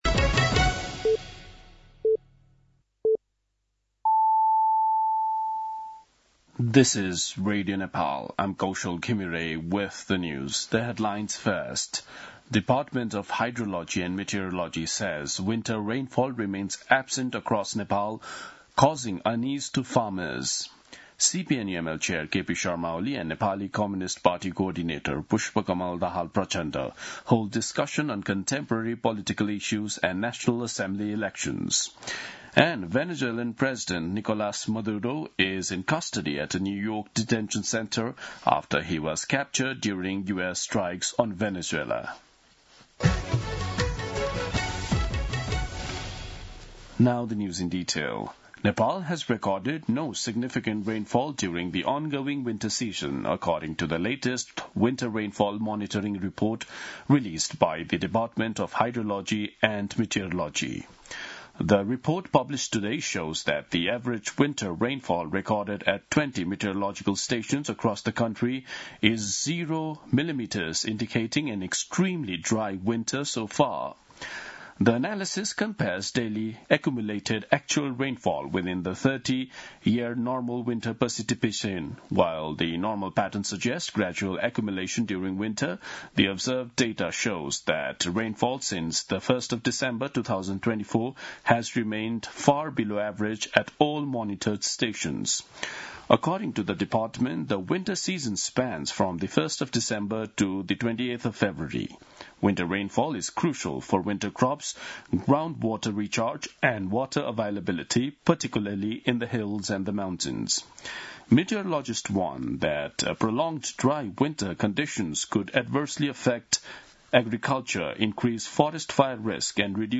दिउँसो २ बजेको अङ्ग्रेजी समाचार : २० पुष , २०८२
2-pm-English-News-9-20.mp3